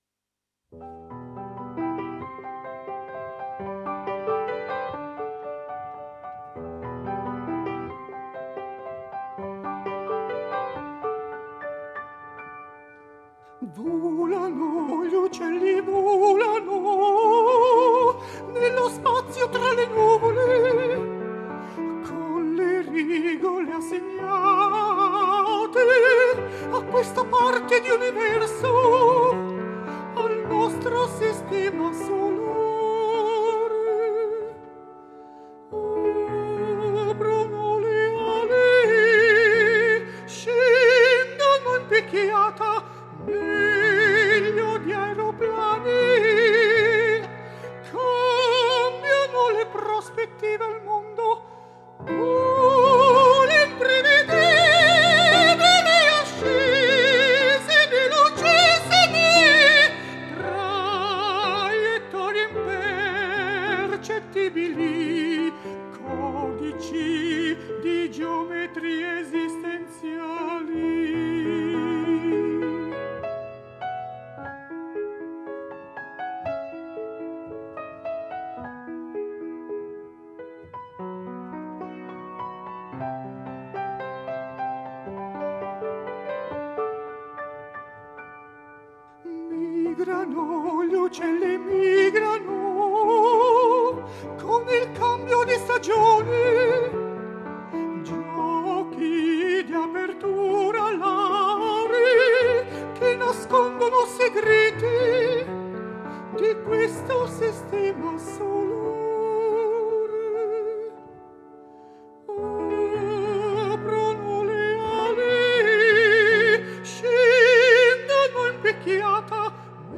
Dieci lieder per dieci canzoni
Sopranista
pianista